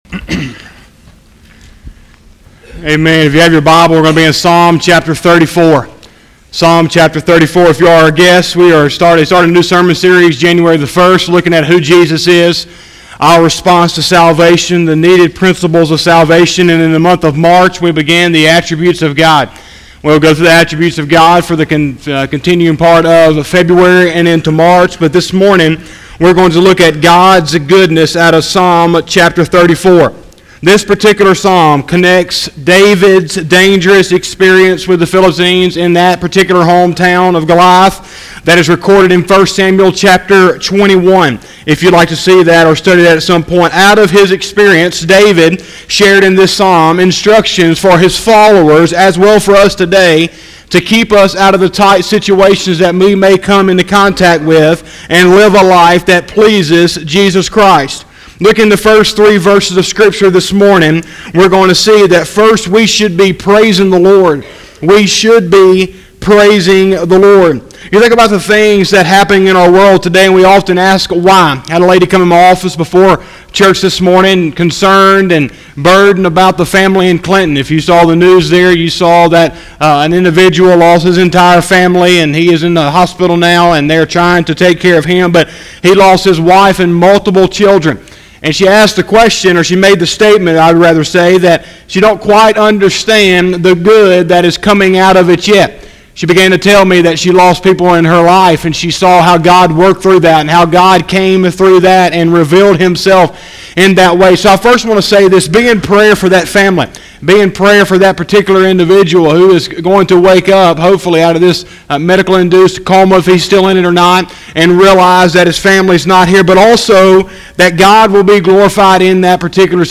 02/09/2020 – Sunday Morning Service